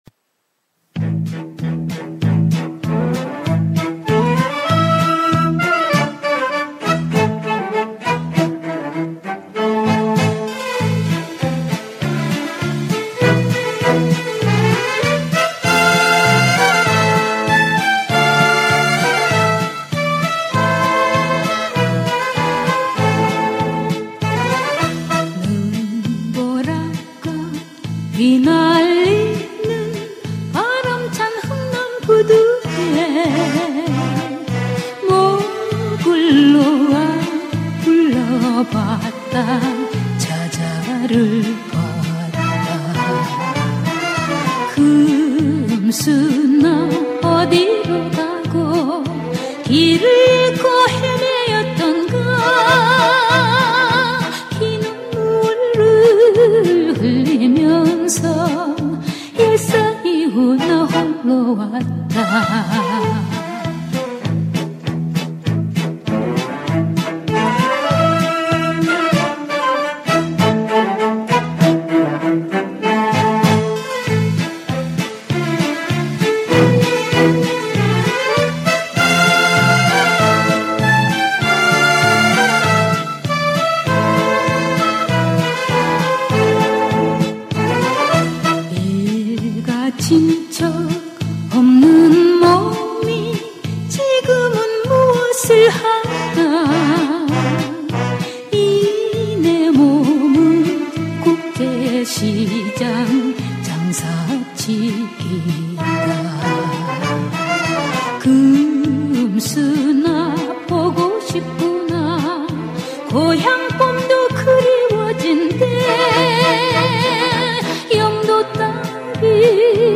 ※ 복각한 음원